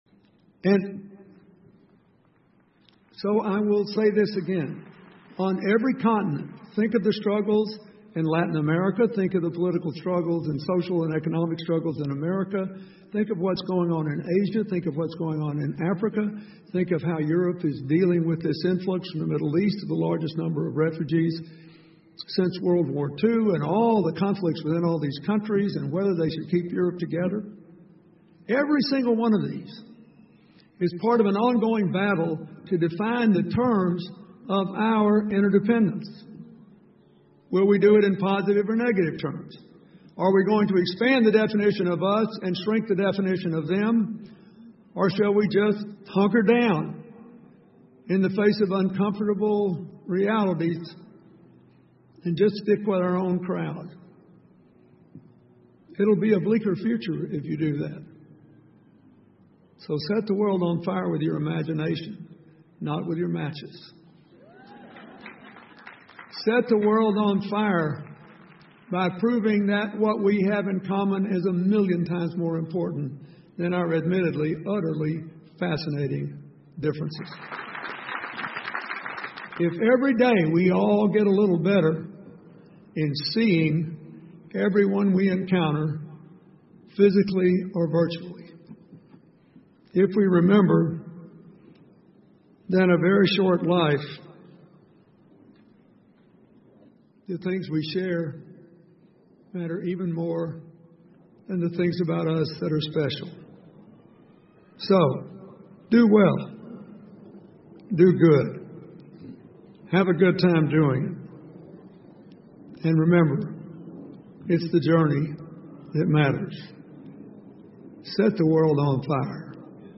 英文演讲录 比尔·克林顿：点燃世界(4) 听力文件下载—在线英语听力室